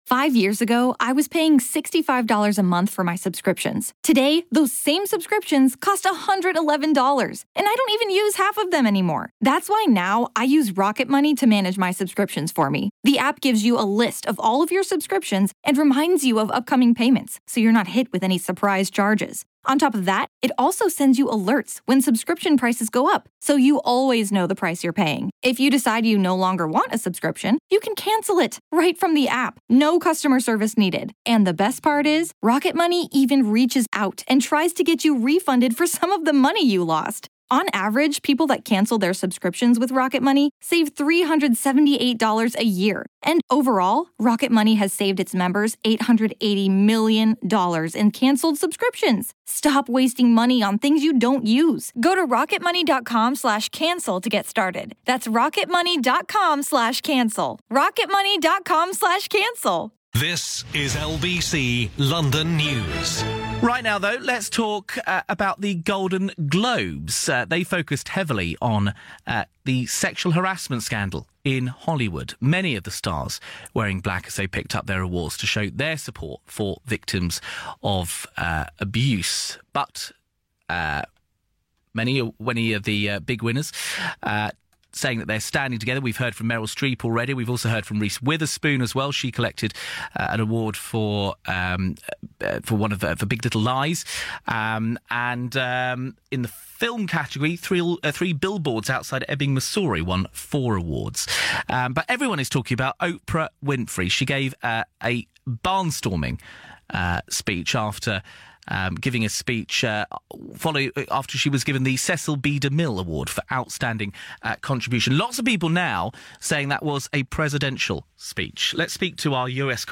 report on speculation that Oprah Winfrey might challenge Donald Trump for the White House in 2020, via the UK's rolling news station LBC London News